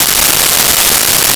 Nuclear Scanner Loop
Nuclear Scanner Loop.wav